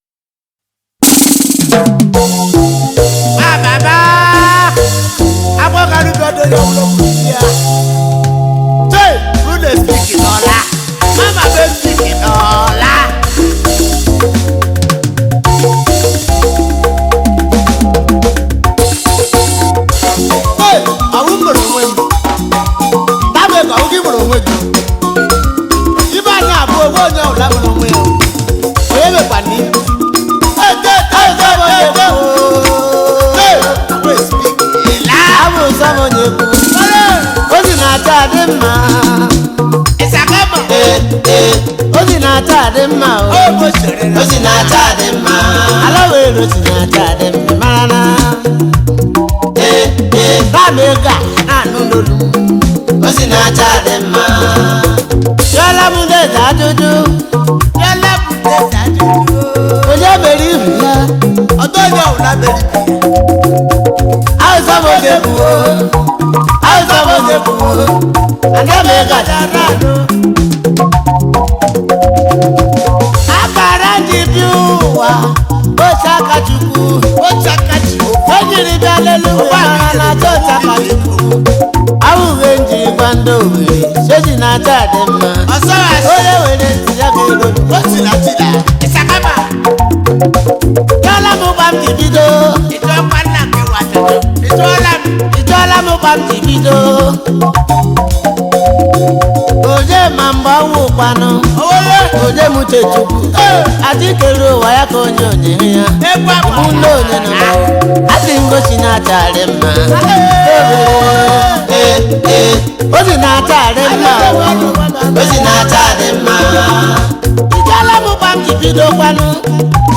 highlife track